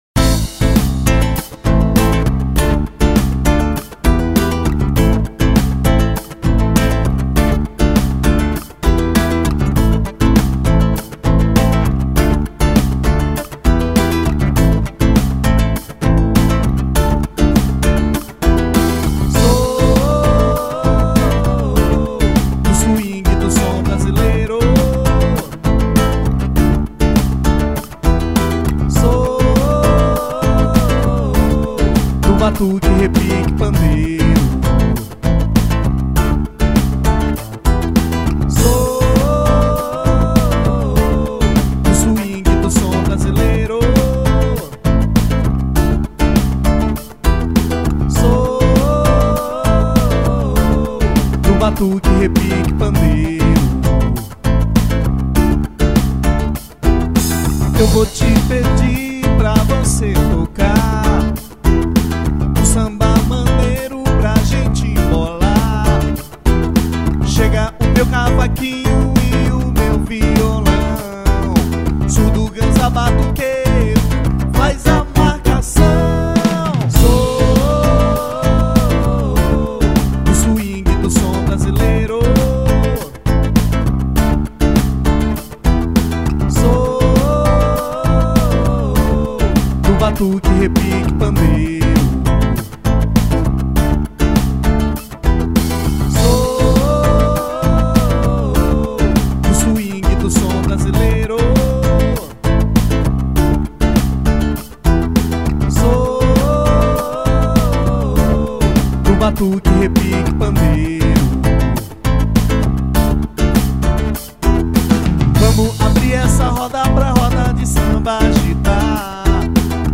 2015   04:31:00   Faixa:     Rock Nacional